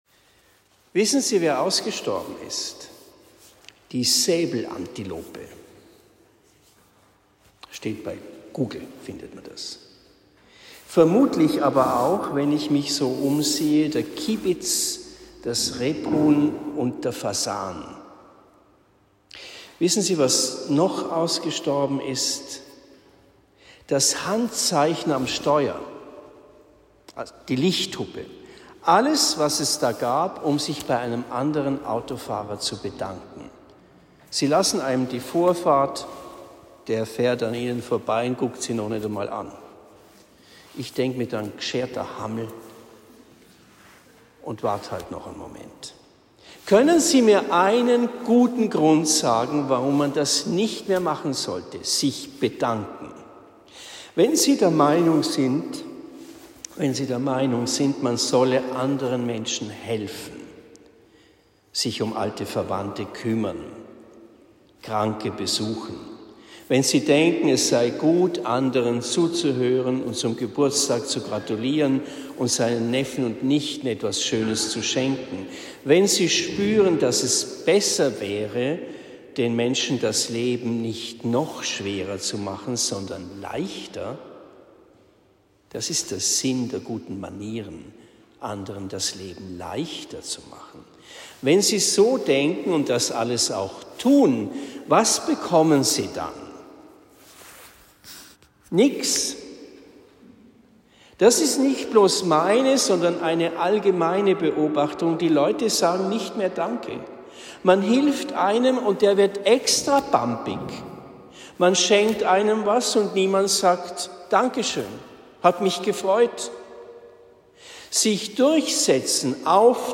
Predigt am 01. Oktober 2023 in Erlenbach